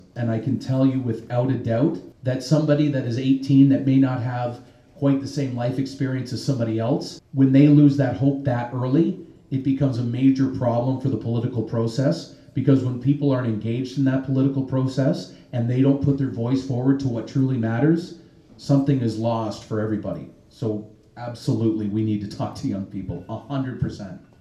A large group of citizens at St. Joseph’s Parish was engaged in the discussion for close to two hours.